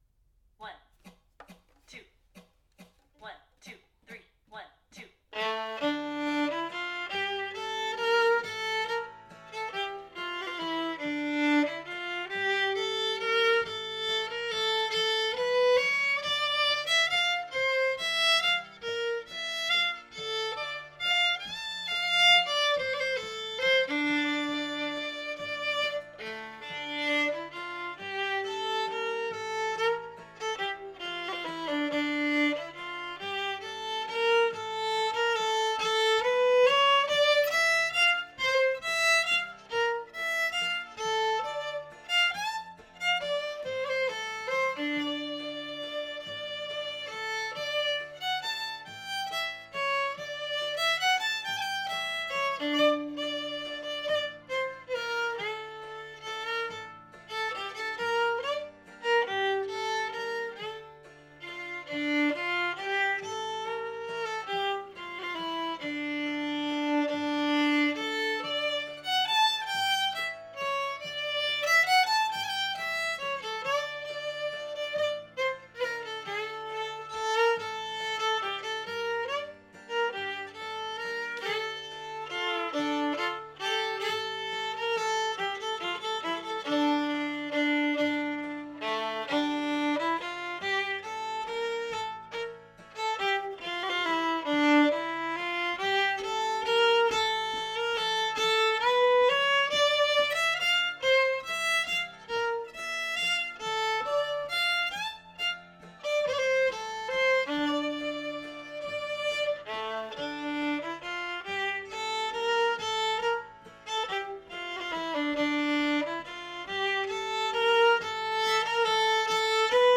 Sessions are open to all instruments and levels, but generally focus on the melody.
Composer Bob McQuillen Type Waltz Key D minor Recordings